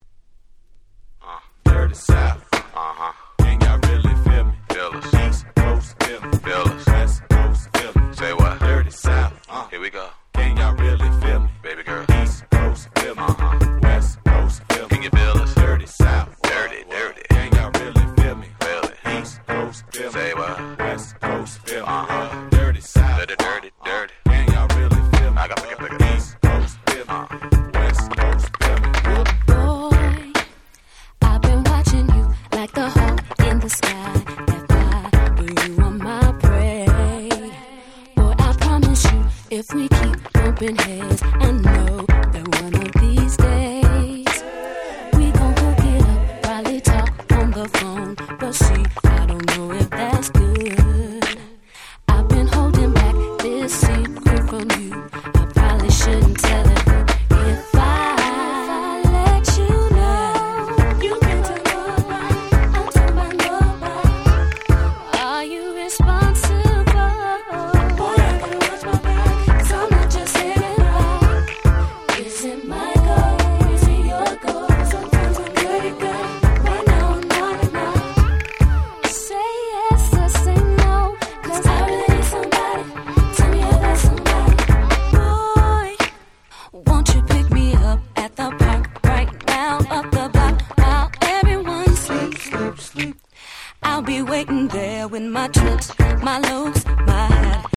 98' Smash Hit R&B !!
チキチキ期90's Super Classicsです！！